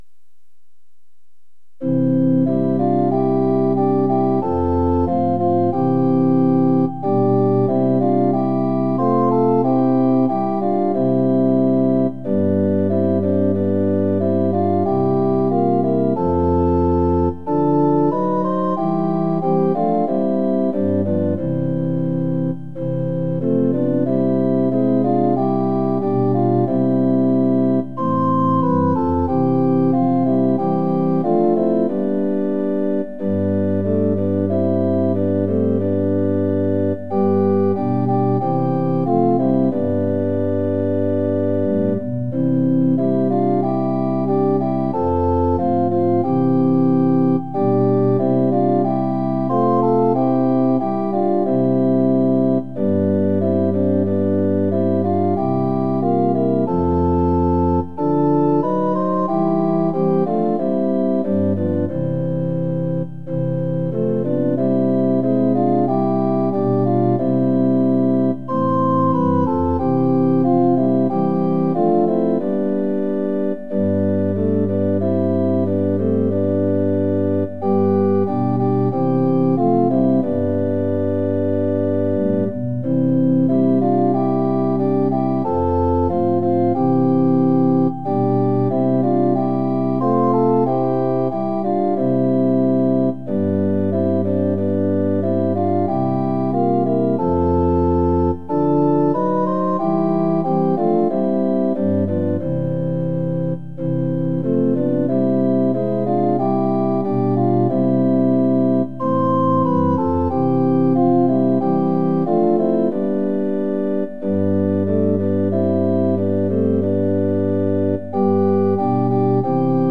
◆　４分の４拍子：　１拍目から始まります。